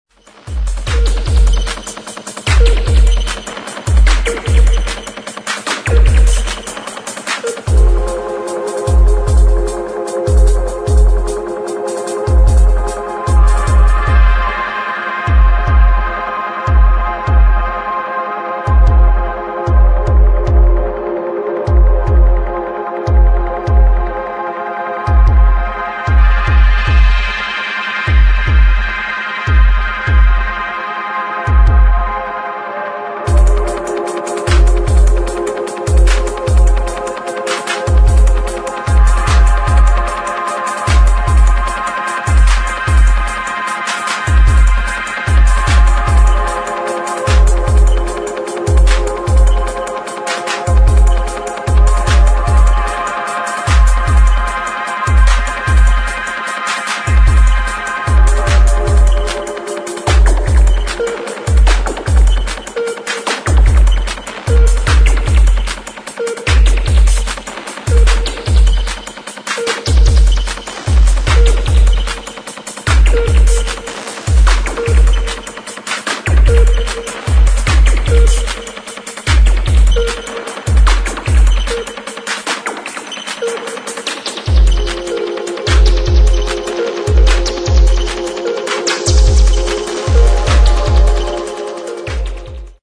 [ TECHNO / BASS ]